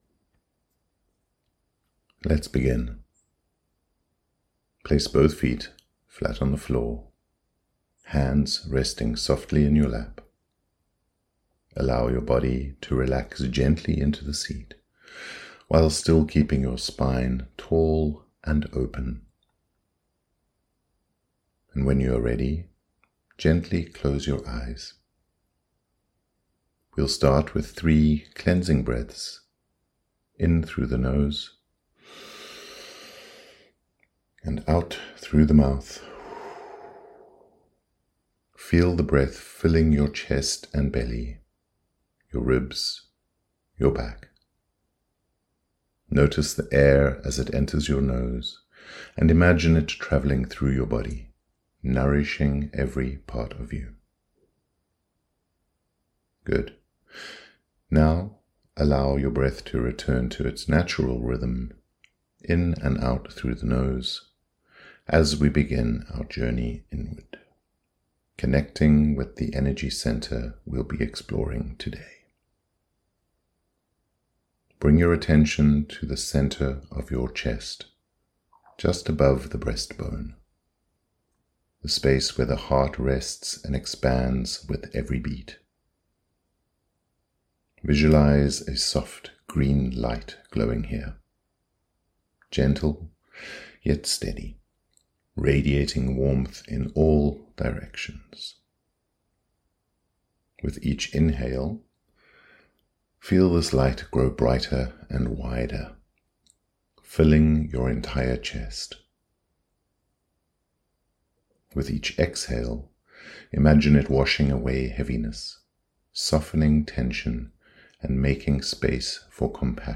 heart Chakra Meditation
CH04-meditation.mp3